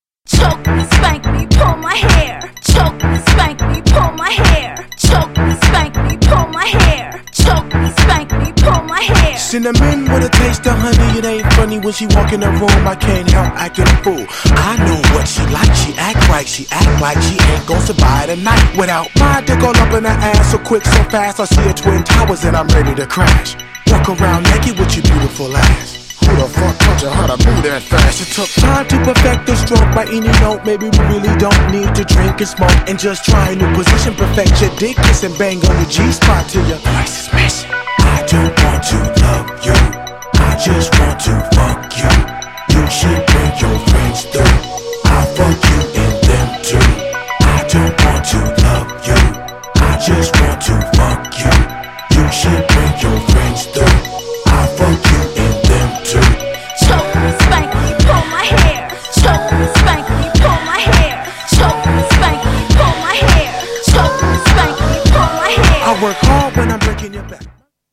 GENRE Hip Hop
BPM 86〜90BPM